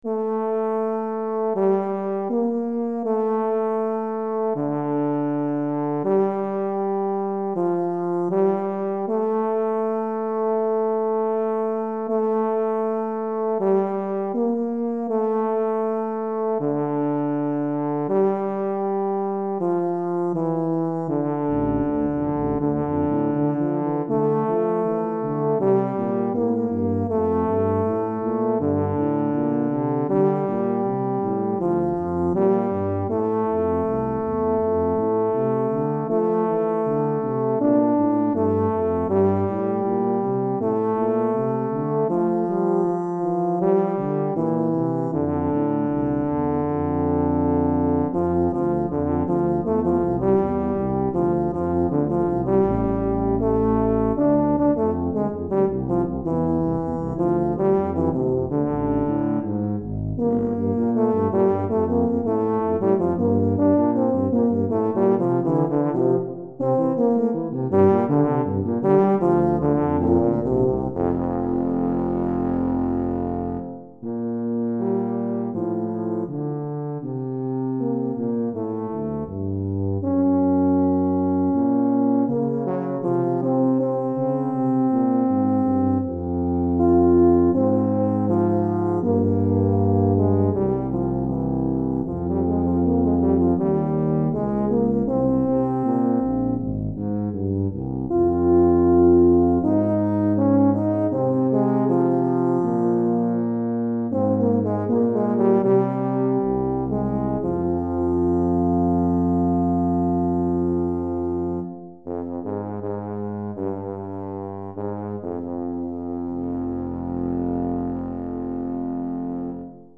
Répertoire pour Musique de chambre - Euphonium et Tuba